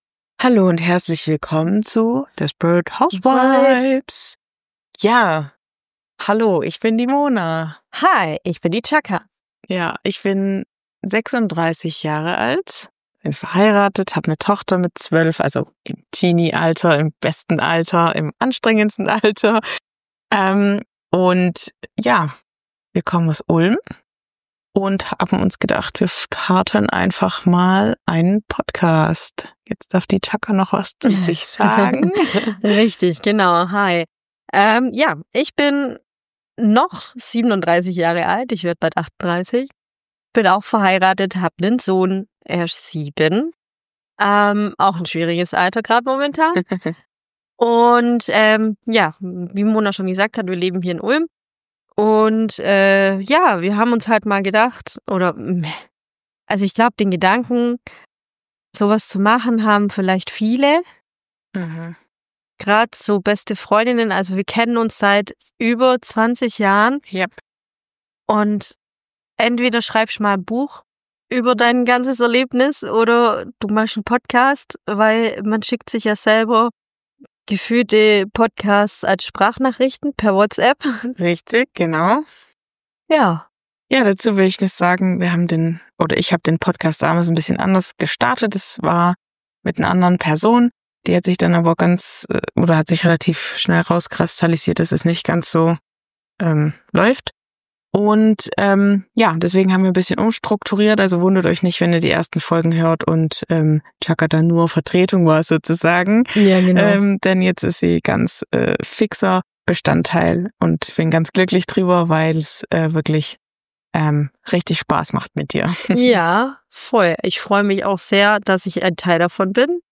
Desperate House Vibes – Der Podcast von besten Freundinnen für
ehrlicher Girl-Talk.